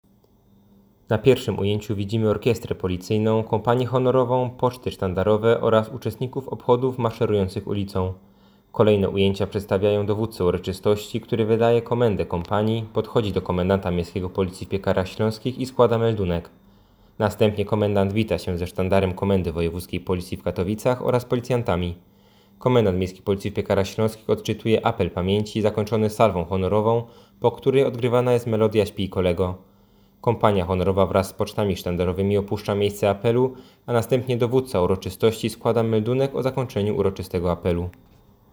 Opis nagrania: Audiodeskrypcja filmu